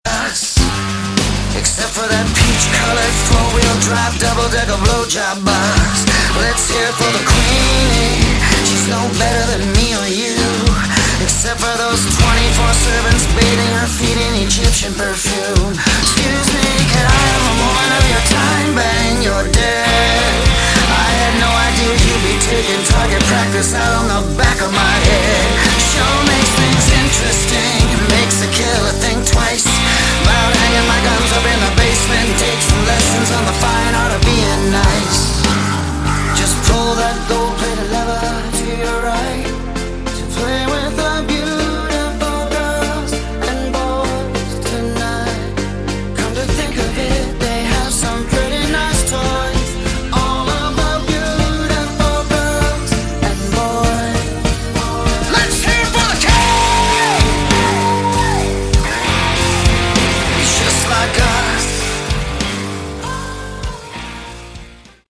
Category: Melodic Rock
lead vocals, guitars, piano
drums, percussion, electronics
keyboards
electric and acoustic bass, vocals